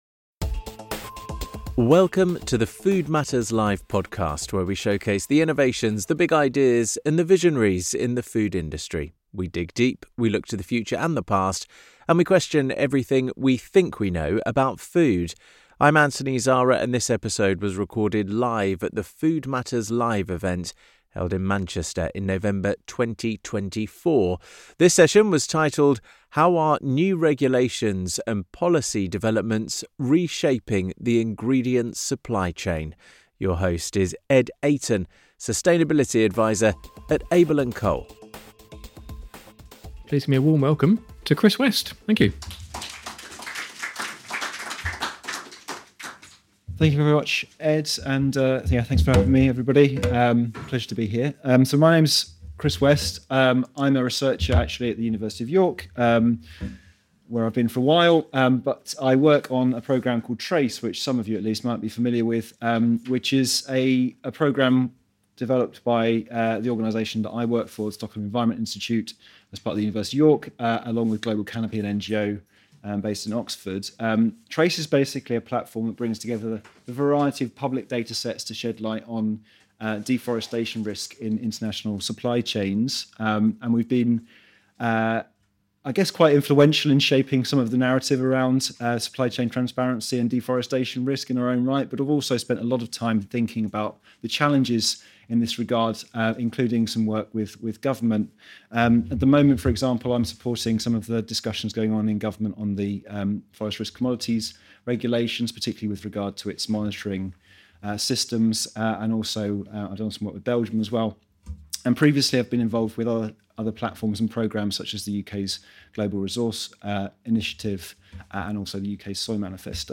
In this episode of the Food Matters Live podcast, recorded live at our event in Manchester in November 2024, an expert panel offer their insights in this fascinating and evolving area. They explore the complexities of deforestation in international supply chains and analyse the role the UK and Europe has in global deforestation, with key commodities such as cattle, soy, cocoa, and palm oil contributing significantly.